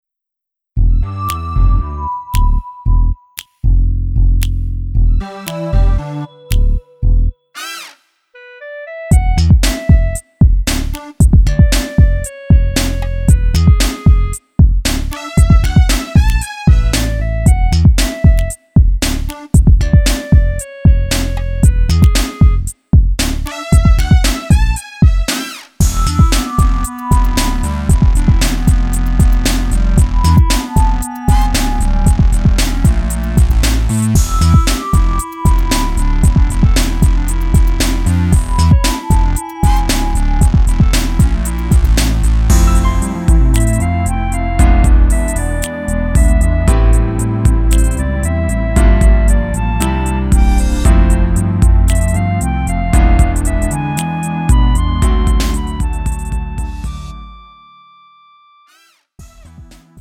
음정 -1키 3:09
장르 가요 구분 Lite MR